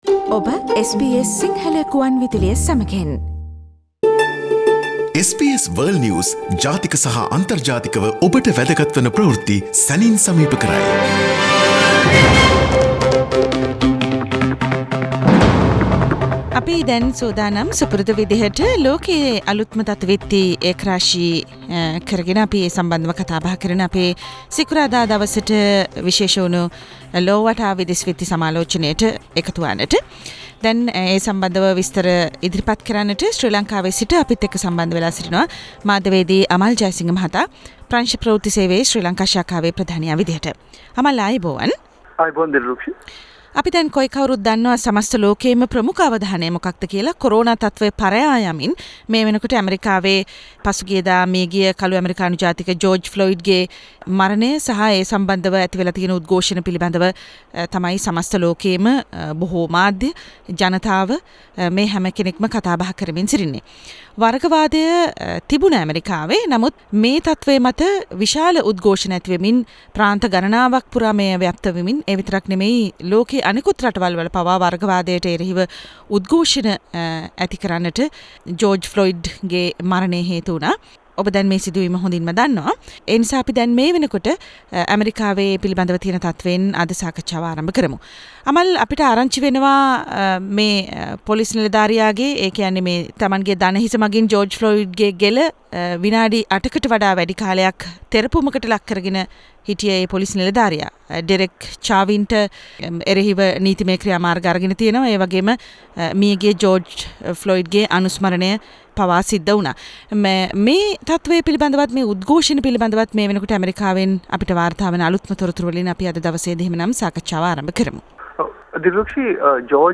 US Secretary of Defense refuse to accept Trump's Laws to Suppress Rebellion and the revelation of Floyd’s corona infection: Weekly world news wrap